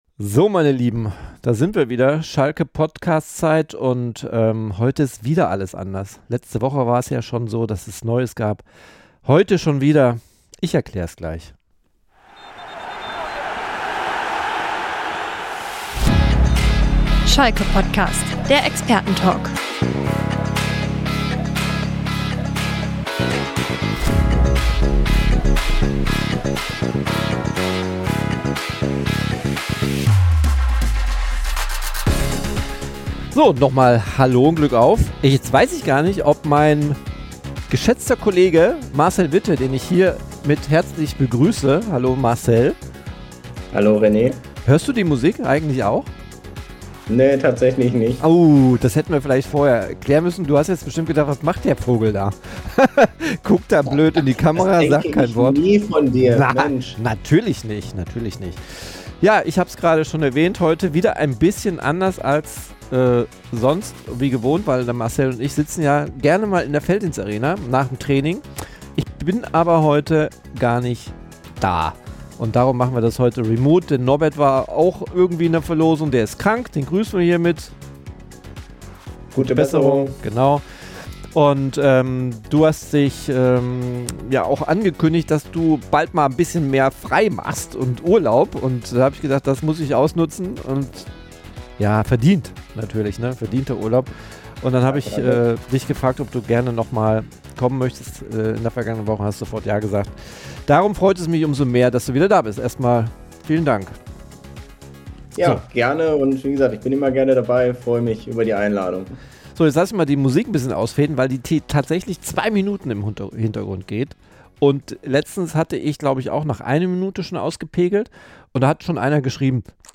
Schalke POTTcast - Der Experten-Talk zu S04